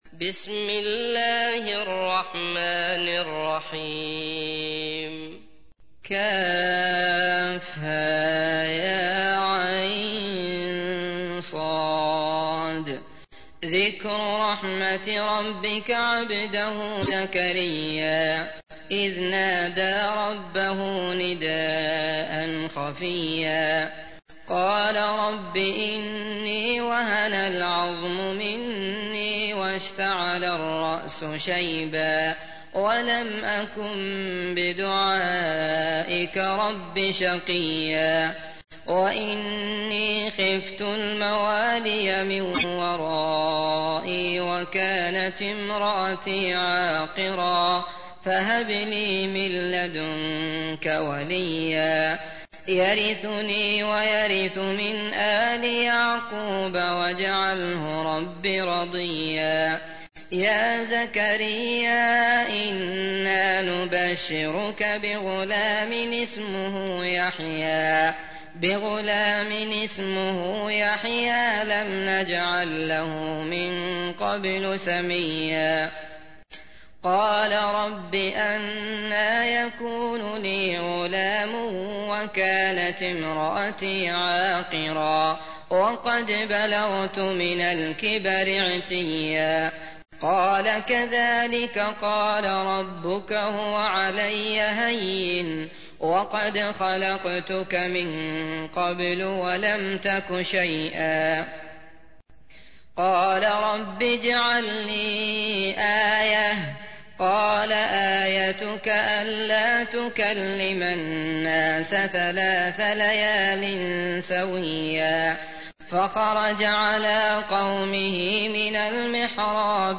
تحميل : 19. سورة مريم / القارئ عبد الله المطرود / القرآن الكريم / موقع يا حسين